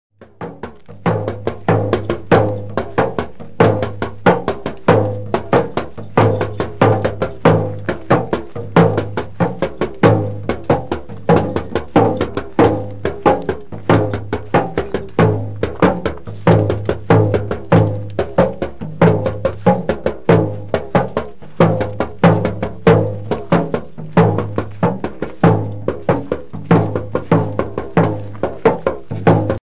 Drums & Idiophones
Ma ka Daŋ  Double-headed cylindrical snare (Ganga-type) drum covered with goatskin and beaten at one end with a bent drum stick and at the other with the hand.
Rwa daŋ    An hourglass dru (corresponding to the Hausa kalangu) played with a bent wooden drumstick or with the hands.